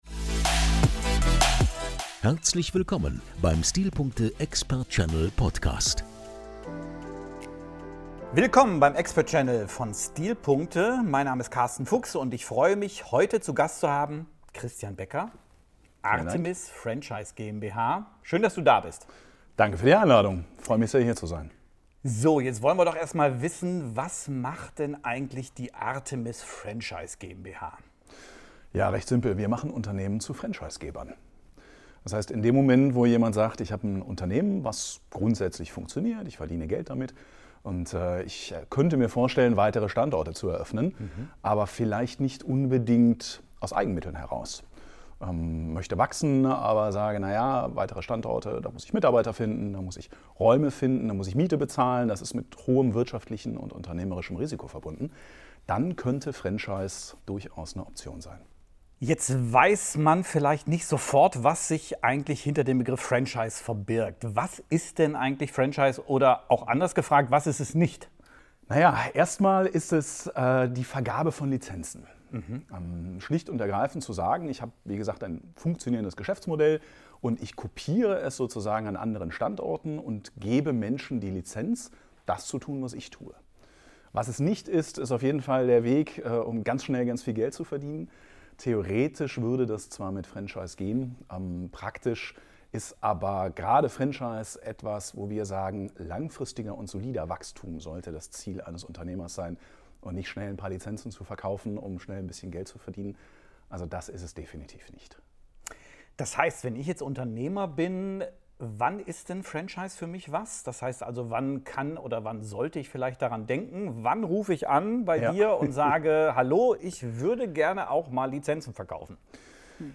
Der STILPUNKTE EXPERT CHANNEL bietet Ihnen faszinierende Einblicke und ausführliche Interviews mit führenden Experten und innovativen Unternehmern aus verschiedenen Branchen.